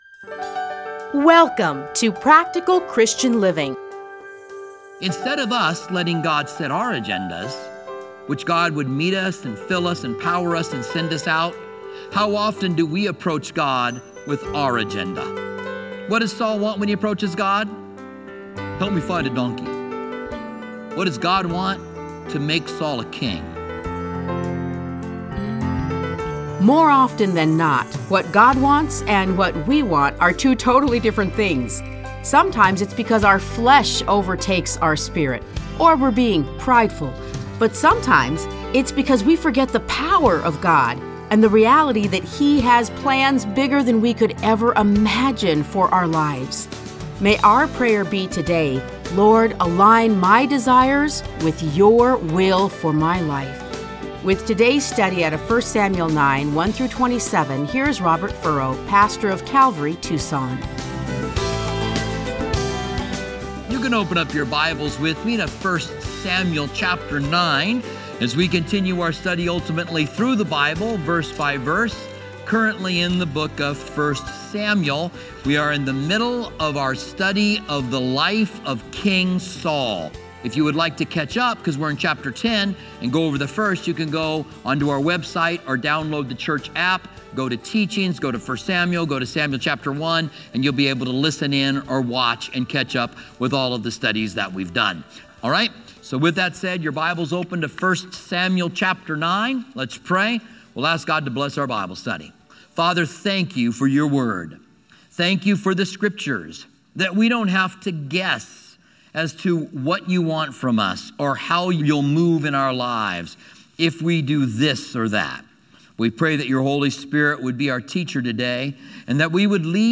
Listen to a teaching from 1 Samuel 9:1-27.